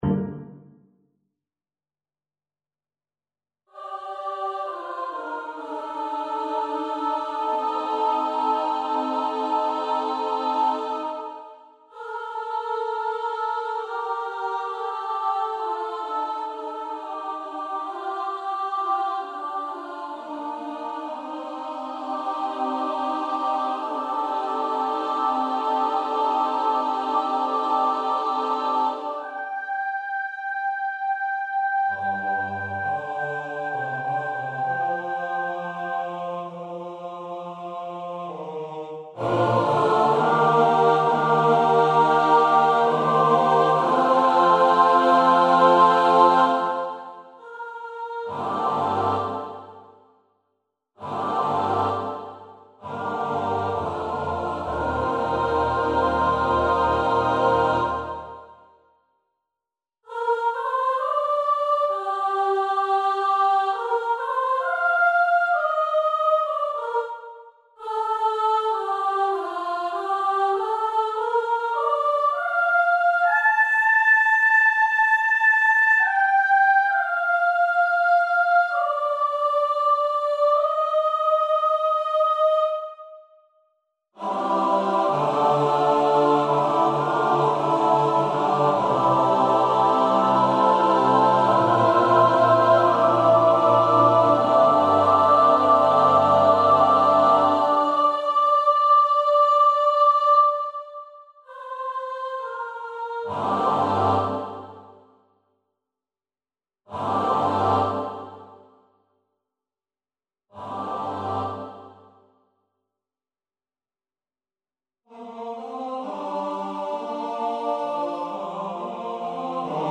Beat The World, The War - SATB div.
I have this large choir piece that I recently wrote, and I'm wondering if anybody would like to give feedback on it?